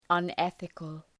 {ʌn’eɵıkəl}